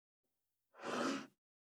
395,スー,ツー,サッ,シュッ,スルッ,ズズッ,スッ,コト,トン,ガタ,ゴト,カタ,ザッ,ヌルッ,キュッ,ギギッ,シャッ,スリッ,ズルッ,シャー,
効果音厨房/台所/レストラン/kitchen
効果音